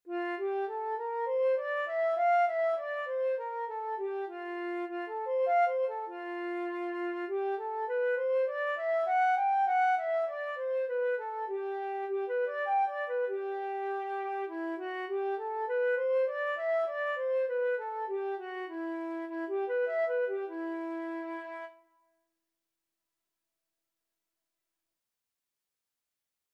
Flute scales and arpeggios - Grade 1
4/4 (View more 4/4 Music)
E5-G6
F major (Sounding Pitch) (View more F major Music for Flute )
flute_scale_grade1.mp3